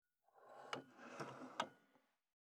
397,ツー,サッ,シュッ,スルッ,ズズッ,スッ,コト,トン,ガタ,ゴト,
効果音厨房/台所/レストラン/kitchen